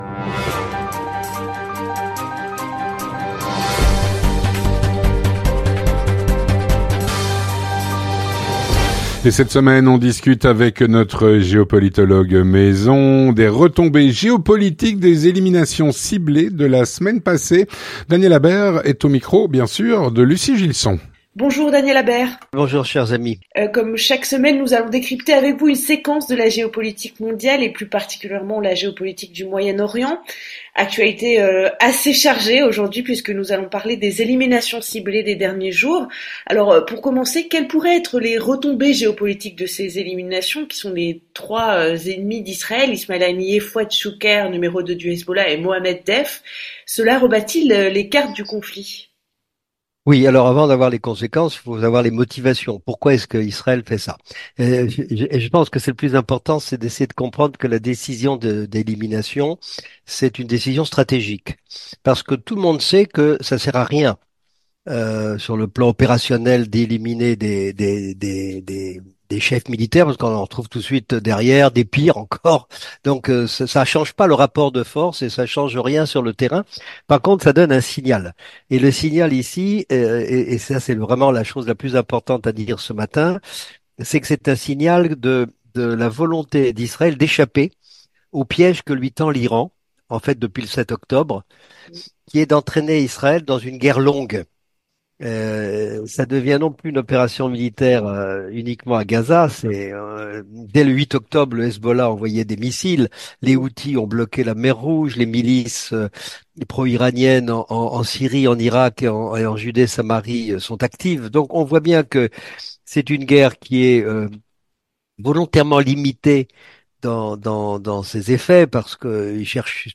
Cette semaine, on discute des retombées géopolitiques des éliminations ciblées de la semaine dernière.